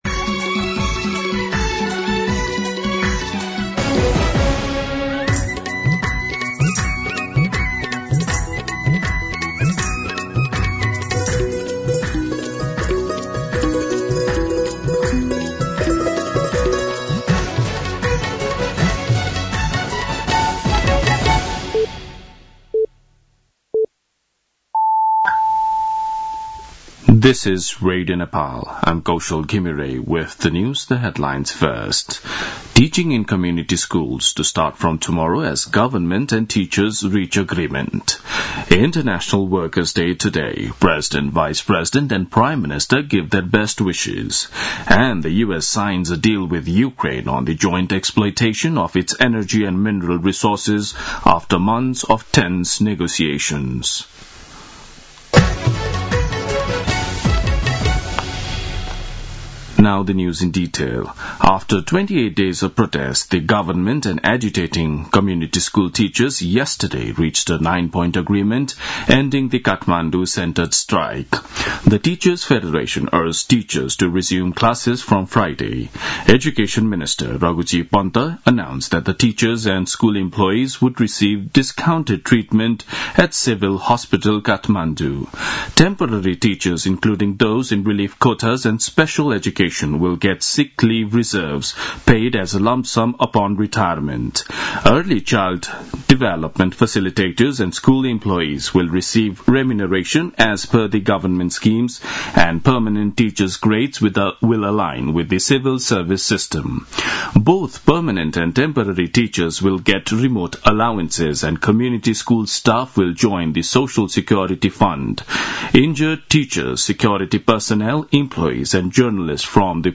दिउँसो २ बजेको अङ्ग्रेजी समाचार : १८ वैशाख , २०८२